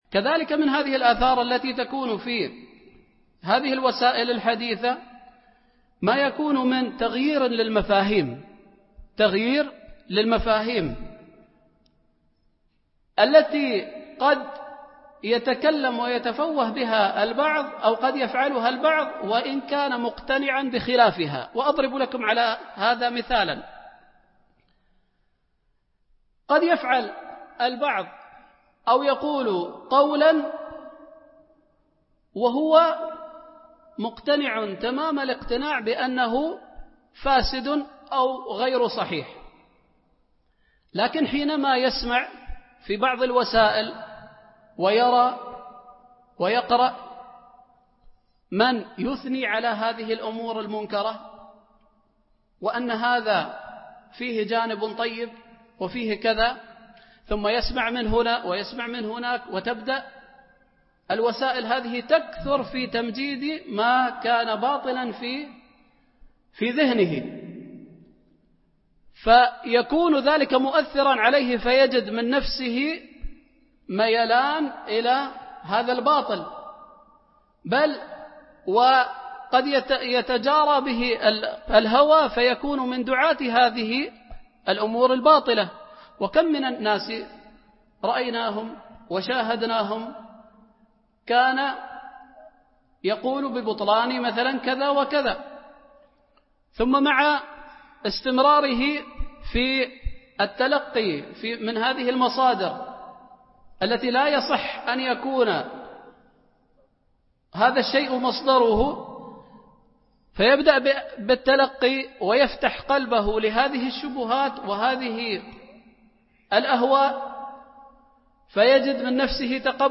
majmoueat-machaikhe-nadwa-ilmiya-7-aleinhirafe-aleakhlaki-inda-achababe-asbabe-wa-ilaje-02.mp3